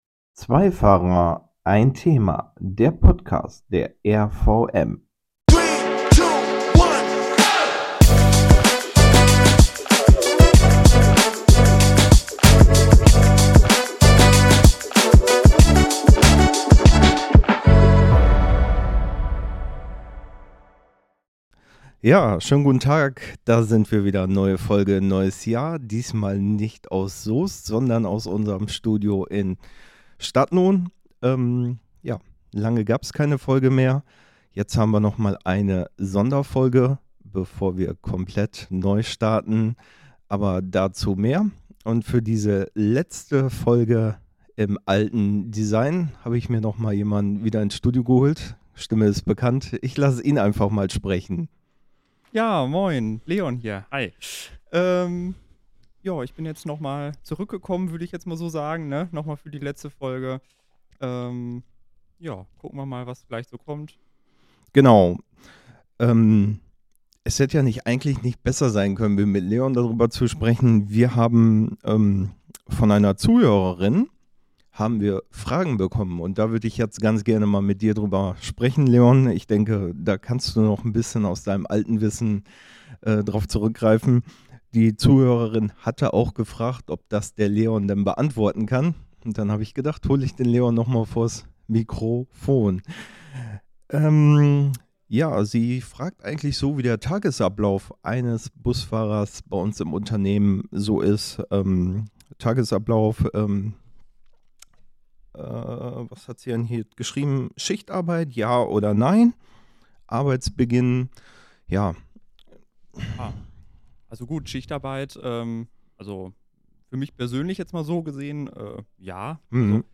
Die beiden Bus-Profis gehen auf unterschiedliche Fragen rund um den Busverkehr aus der Zuhörerschaft ein: Gewohnt authentisch und gespickt mit tollen Geschichten aus den letzten Jahren im Fahrdienst.